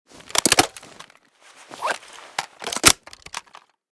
m14_reload.ogg.bak